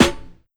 Hot Snare.wav